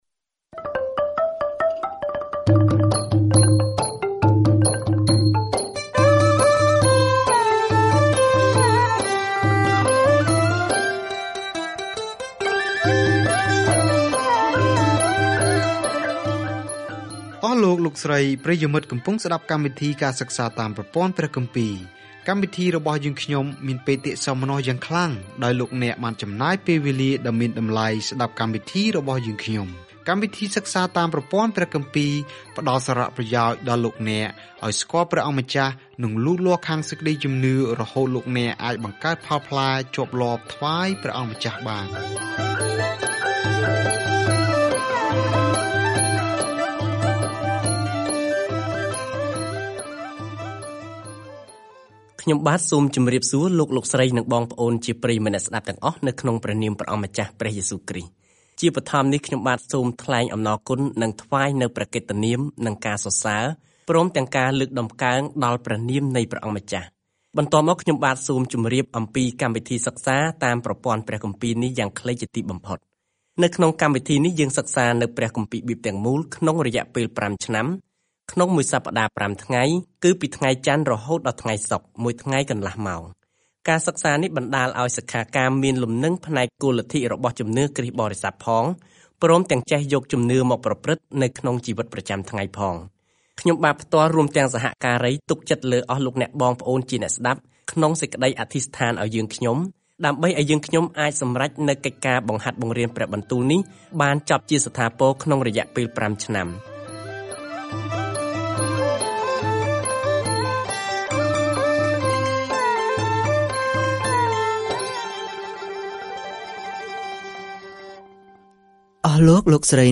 ការធ្វើដំណើរជារៀងរាល់ថ្ងៃតាមរយៈអែសរ៉ា នៅពេលអ្នកស្តាប់ការសិក្សាជាសំឡេង ហើយអានខគម្ពីរដែលជ្រើសរើសពីព្រះបន្ទូលរបស់ព្រះ។